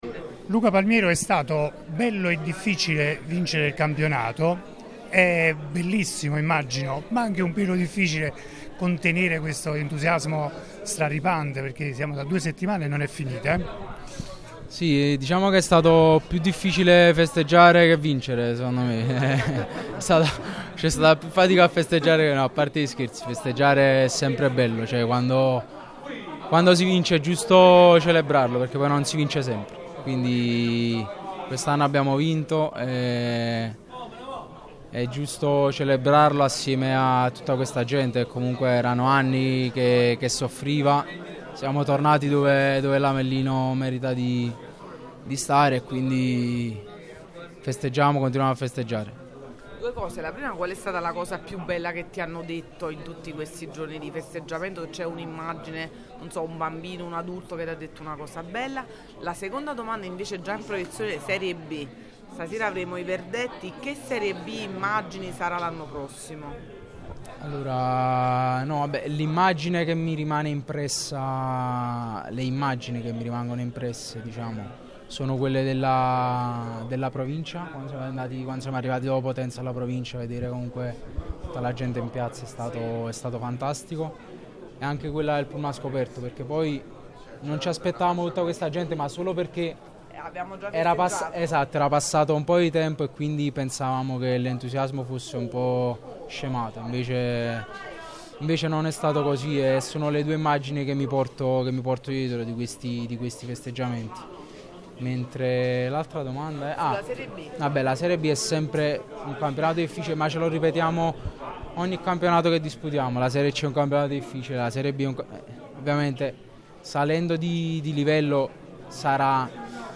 intervenuto ai microfoni di Radio Punto Nuovo nel corso dell’incontro con i tifosi tenutosi nel pomeriggio di martedì 13 maggio presso lo store ufficiale dell’Us Avellino.